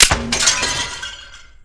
assets/pc/nzp/sounds/weapons/tesla/clipoff.wav at caee5e5ee820adec165e9f461fae90a9ad5bced1
clipoff.wav